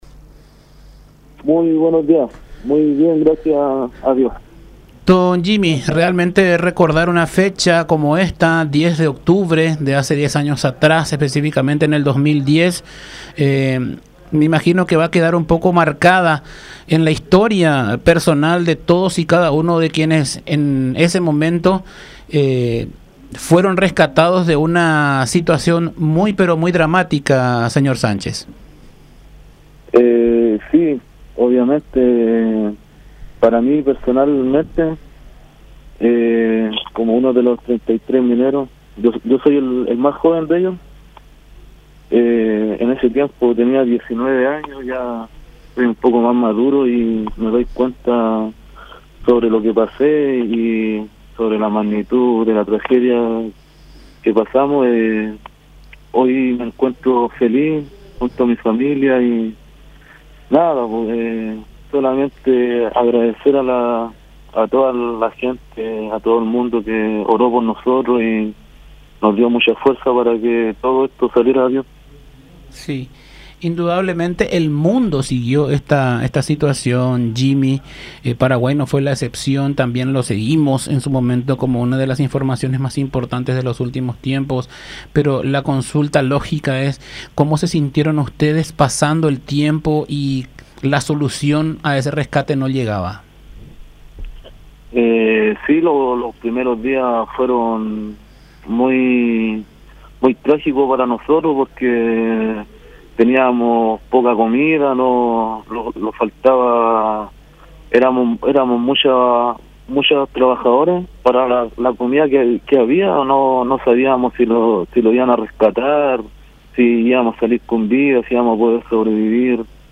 Le agradecimos a él y a todos los que trabajaron en ese tiempo por todo lo que trabajaron y habían hecho por nosotros para que volvamos a compartir con nuestras familias”, rememoró emocionado el minero.